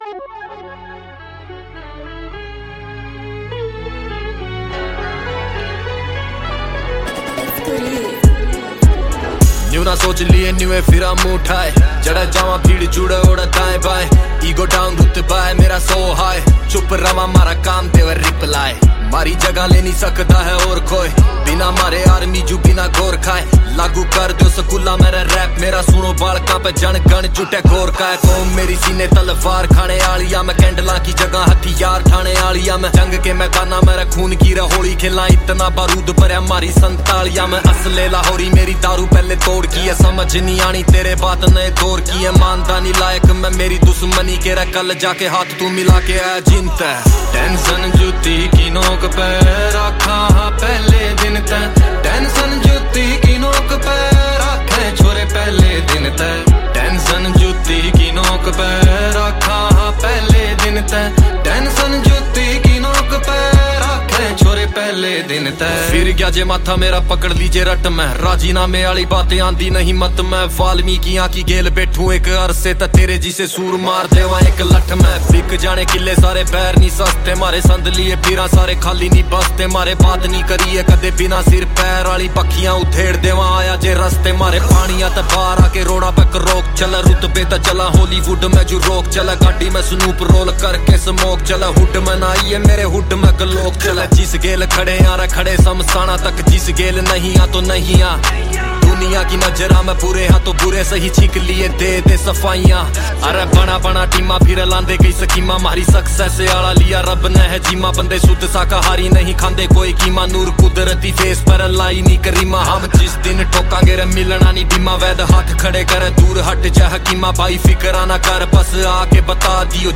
Releted Files Of Latest Haryanvi song